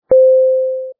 Electronic_Chime_Low2.mp3